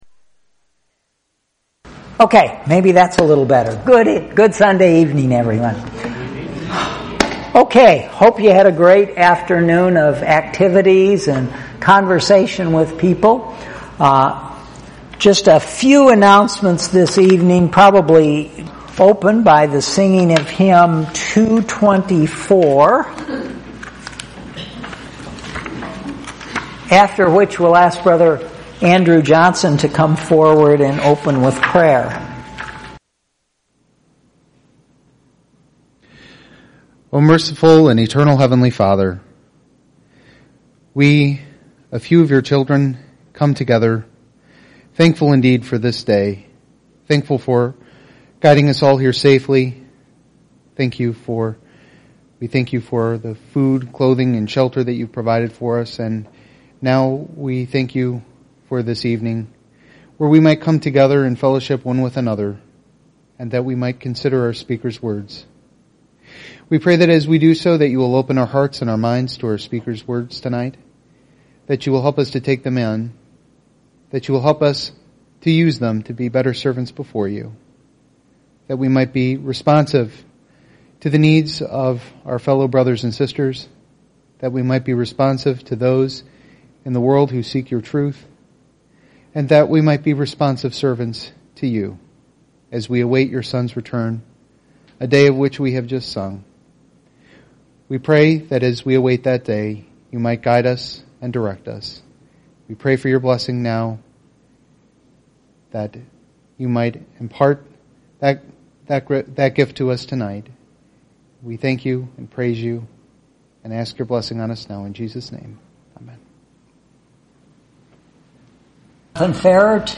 2019 Evening Exhortations – Kentucky Christadelphian Bible School